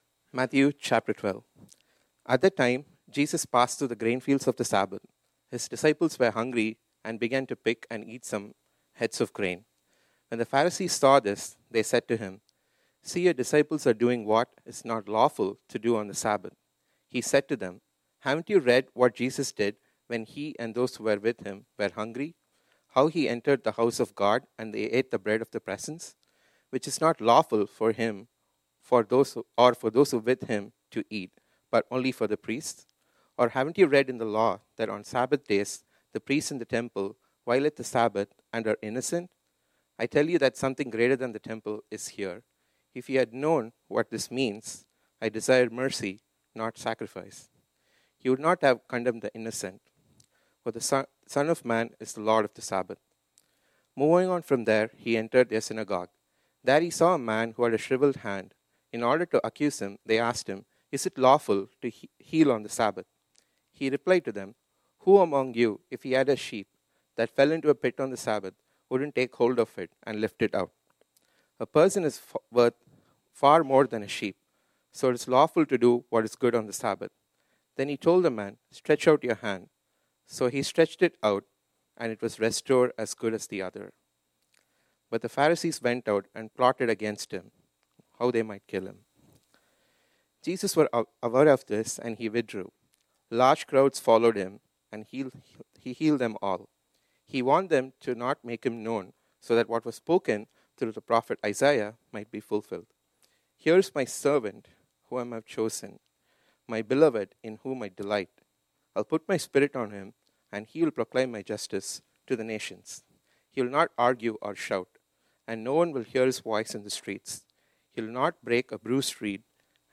This sermon was originally preached on Sunday, May 26, 2024.